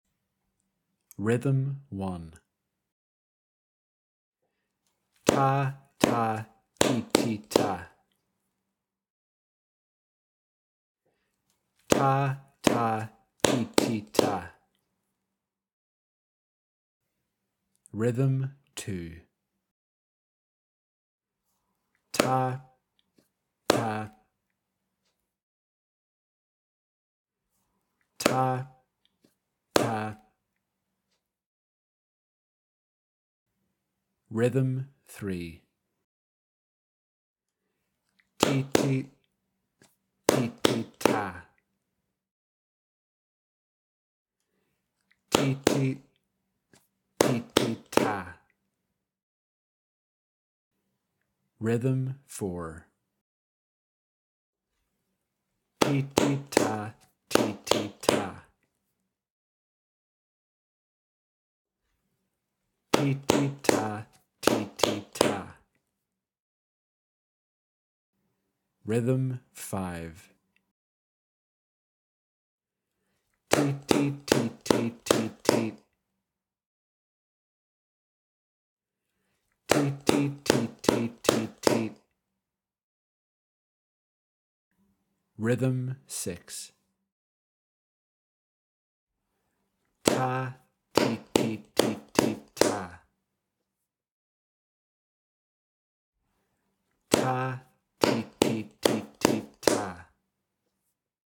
Lesson 2: Four-beat Rhythms